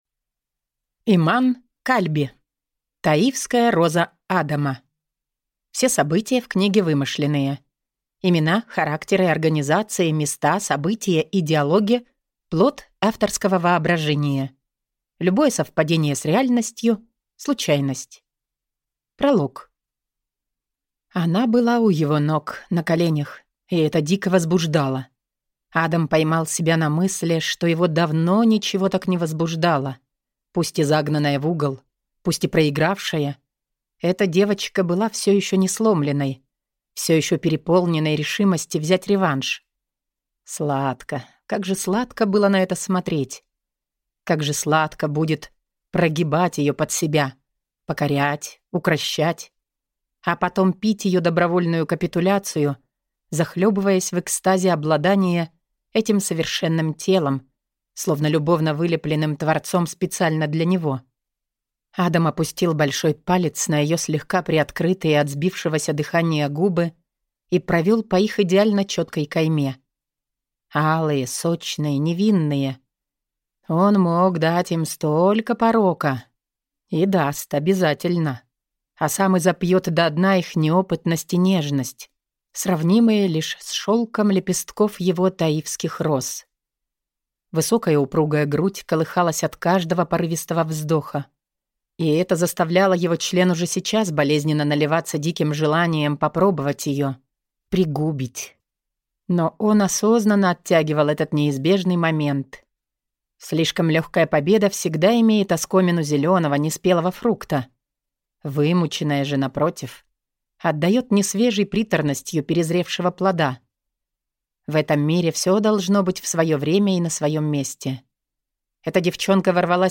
Аудиокнига Таифская роза Адама | Библиотека аудиокниг
Прослушать и бесплатно скачать фрагмент аудиокниги